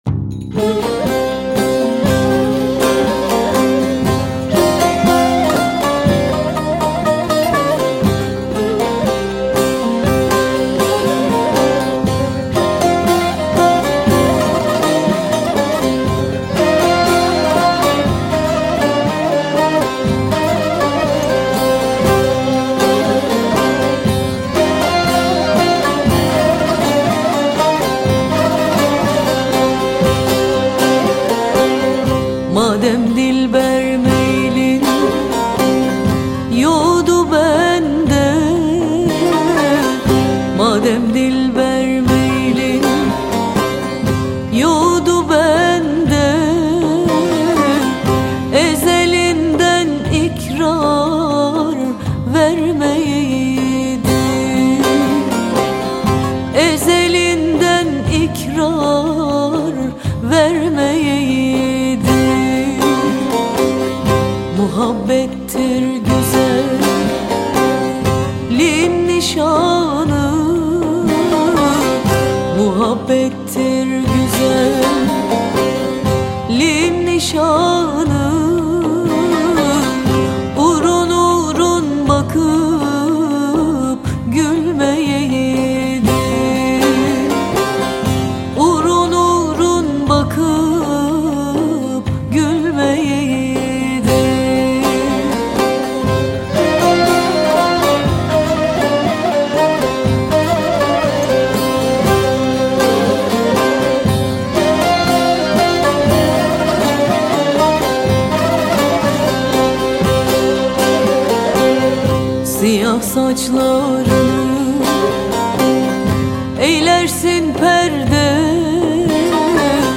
Etiketler: ankara, türkü, müzik, türkiye